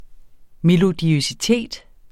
Udtale [ melodiœsiˈteˀd ]